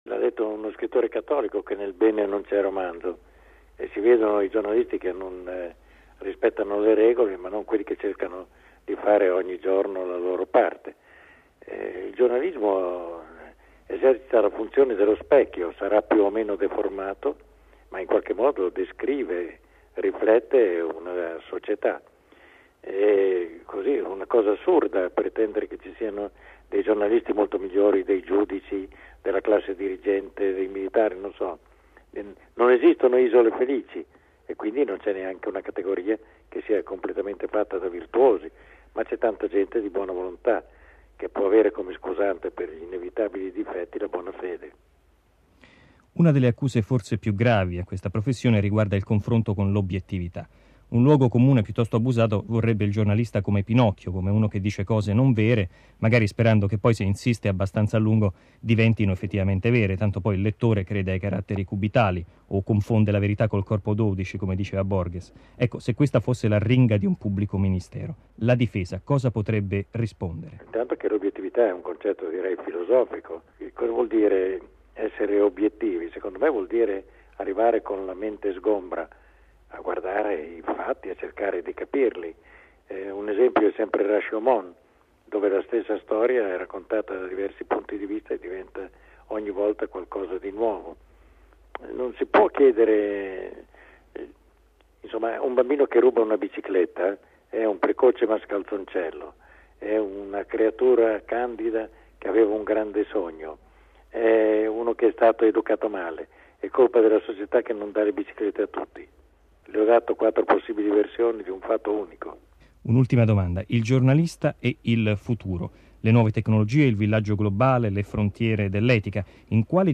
La Radio Vaticana ricorda il celebre giornalista con un'intervista sui valori dell'informazione, concessa qualche tempo fa alla nostra emittente